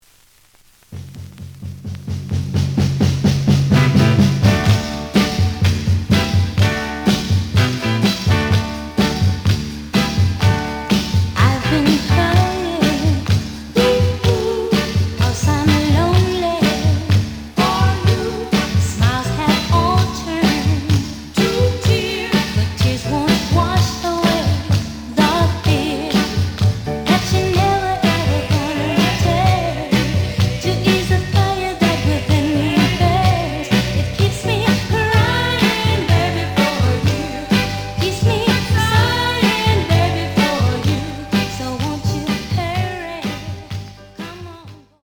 The audio sample is recorded from the actual item.
●Genre: Soul, 60's Soul
Slight affect sound.